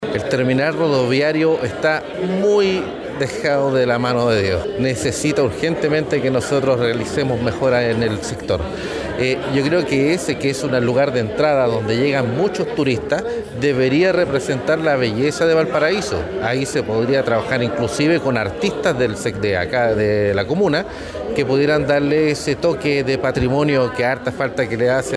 El concejal, Jorge López, apuntó a la necesidad de renovar el espacio, invitando a artistas locales a intervenir el espacio que da llegada a los visitantes en la comuna puerto.